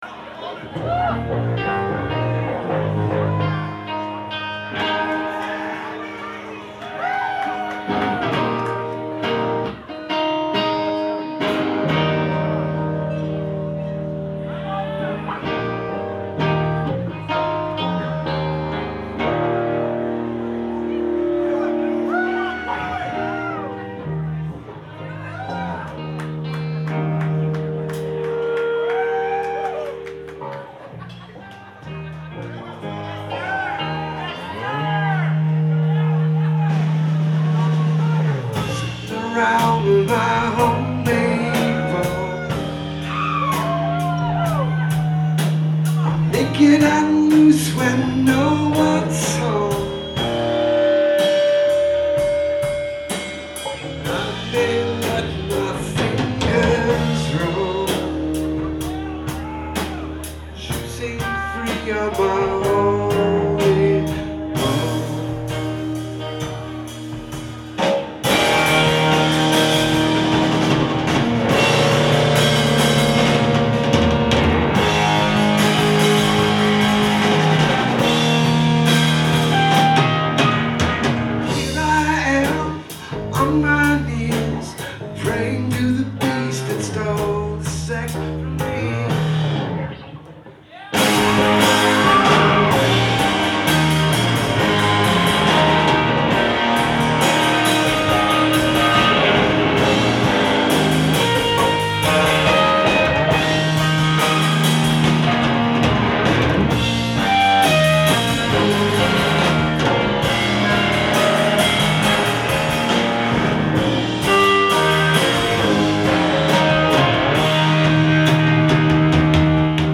Live at the Paradise
in Boston, Massachusetts